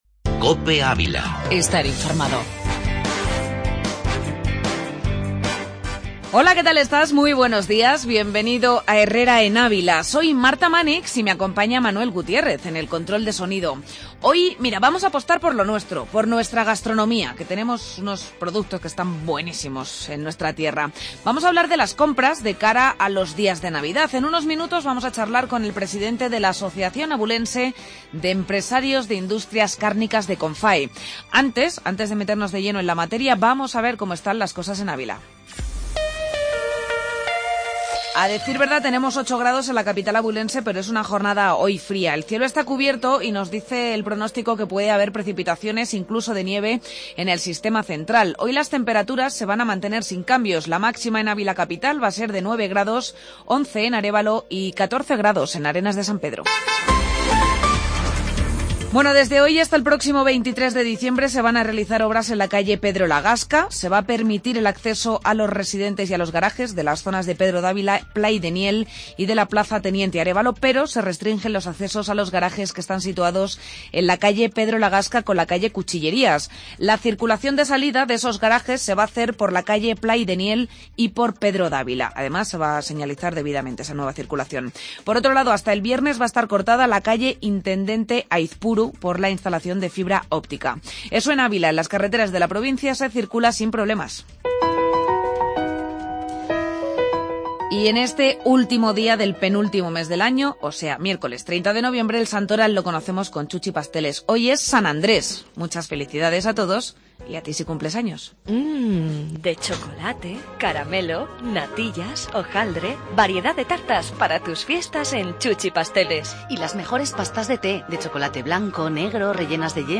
AUDIO: Entrevista Industrias Carnicas